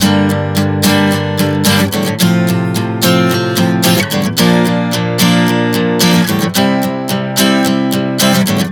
Prog 110 Gm-F-Bb-C.wav